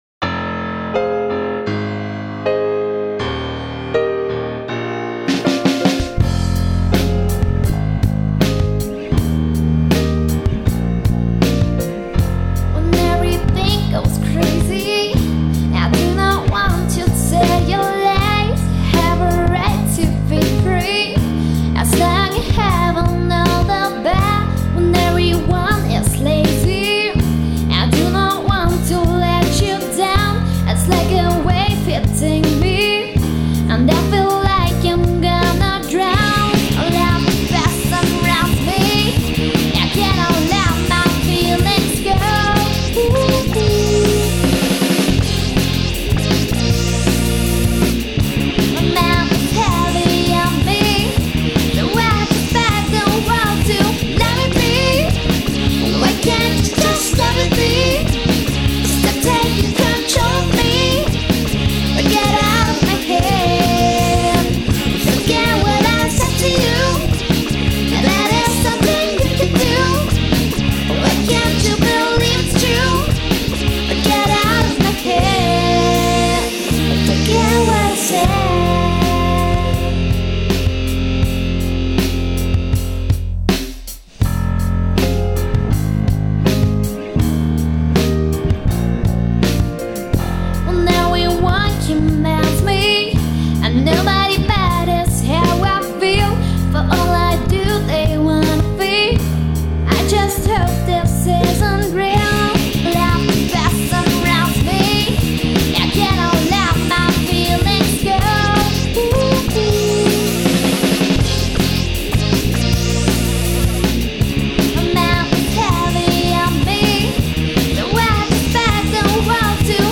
Pop/Rock-Band